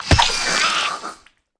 Sawblade Kill Sound Effect
Download a high-quality sawblade kill sound effect.
sawblade-kill.mp3